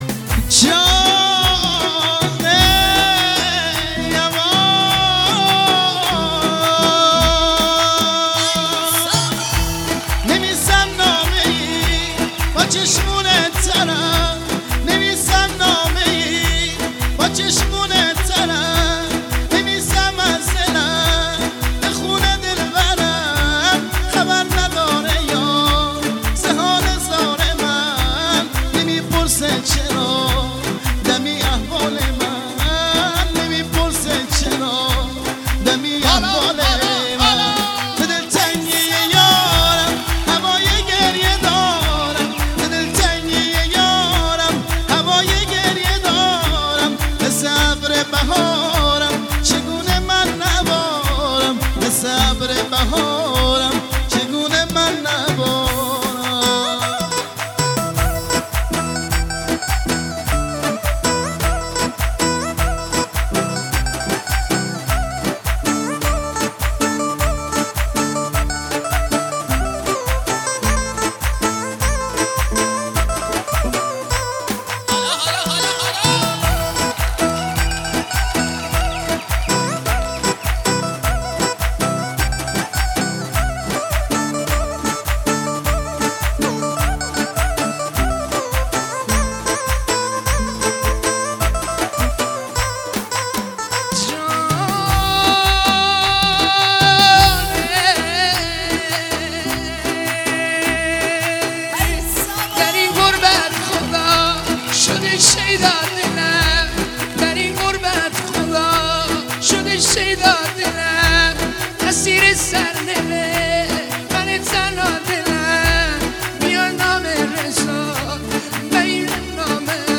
نسخه دو نفره